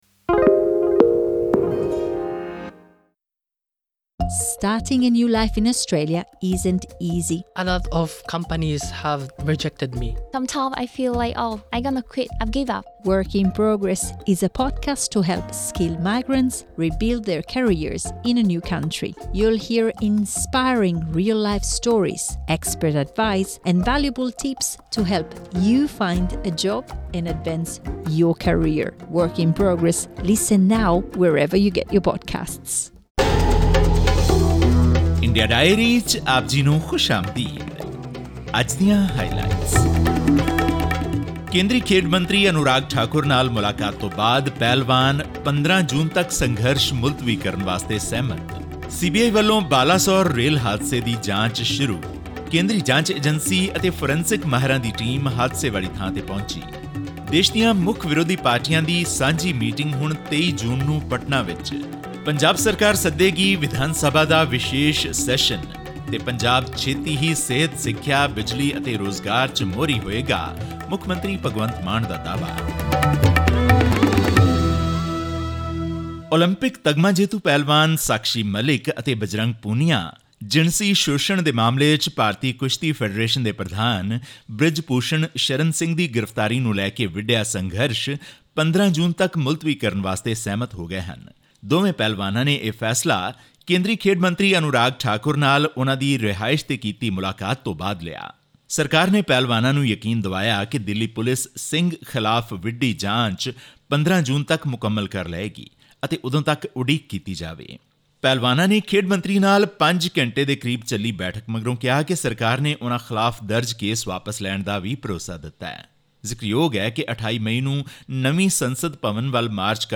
ਬਜਰੰਗ ਪੂਨੀਆ ਅਤੇ ਸਾਕਸ਼ੀ ਮਲਿਕ ਖੇਡ ਮੰਤਰੀ ਅਨੁਰਾਗ ਠਾਕੁਰ ਨੂੰ ਮਿਲਣ ਤੋਂ ਬਾਅਦ ਭਾਰਤੀ ਕੁਸ਼ਤੀ ਫੈਡਰੇਸ਼ਨ ਦੇ ਪ੍ਰਧਾਨ ਬ੍ਰਿਜ ਭੂਸ਼ਣ ਸ਼ਰਨ ਸਿੰਘ ਦੀ ਗ੍ਰਿਫ਼ਤਾਰੀ ਦੀ ਮੰਗ ਨੂੰ ਲੈ ਕੇ ਚੱਲ ਰਹੇ ਭਾਰਤੀ ਪਹਿਲਵਾਨਾਂ ਦੇ ਧਰਨੇ ਨੂੰ 15 ਜੂਨ ਤੱਕ ਮੁਲਤਵੀ ਕਰਨ ਲਈ ਸਹਿਮਤ ਹੋ ਗਏ ਹਨ। ਜ਼ਿਕਰਯੋਗ ਹੈ ਕਿ ਸਰਕਾਰ ਵਲੋਂ ਕੁਸ਼ਤੀ ਫੈਡਰੇਸ਼ਨ ਦੇ ਪ੍ਰਧਾਨ ਵਿਰੁੱਧ ਜਿਨਸੀ ਸ਼ੋਸ਼ਣ ਦੇ ਦੋਸ਼ਾਂ ਦੀ ਤੁਰੰਤ ਜਾਂਚ ਦਾ ਭਰੋਸਾ ਦਵਾਇਆ ਗਿਆ ਹੈ। ਇਹ ਅਤੇ ਭਾਰਤ ਦੀਆਂ ਹੋਰ ਹਫਤਾਵਾਰੀ ਖਬਰਾਂ ਦੀ ਤਫਸੀਲ ਜਾਨਣ ਲਈ ਸੁਣੋ ਇਹ ਖਾਸ ਰਿਪੋਰਟ...